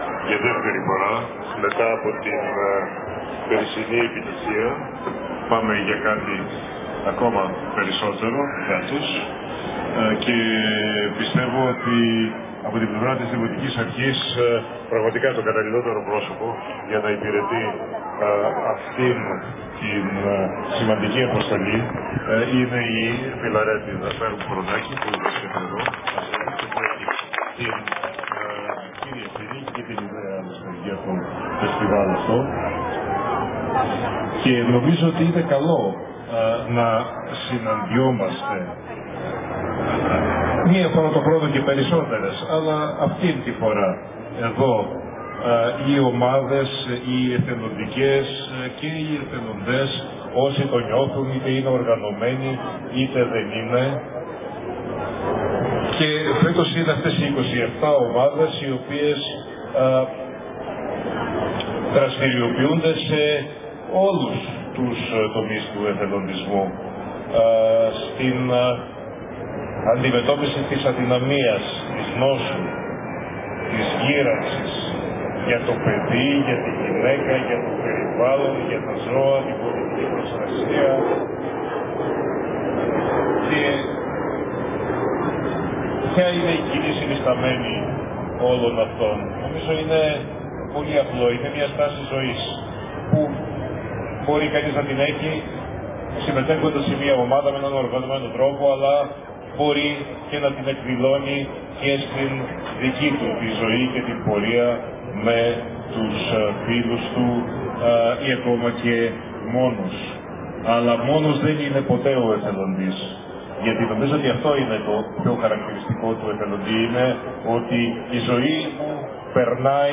Πλατεία Αγίας Αικατερίνης (Άγιος Μηνάς)
Σας παραθέτουμε την ομιλία του Δημάρχου.